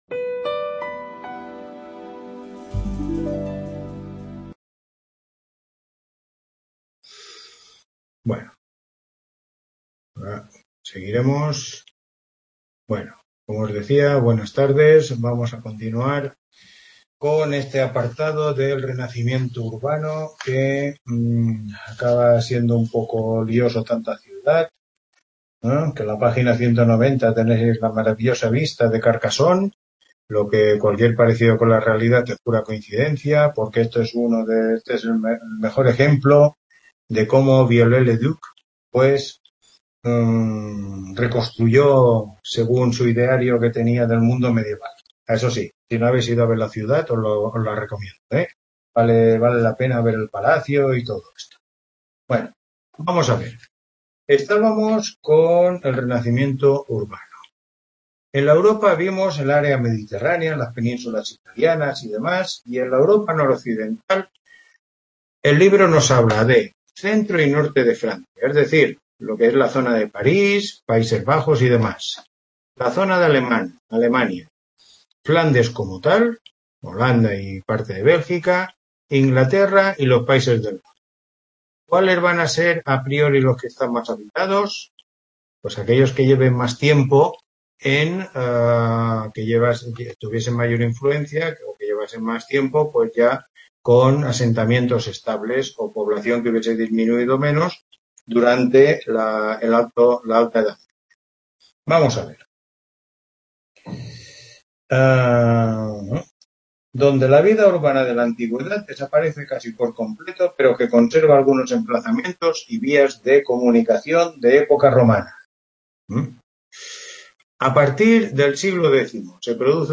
Tutoría 6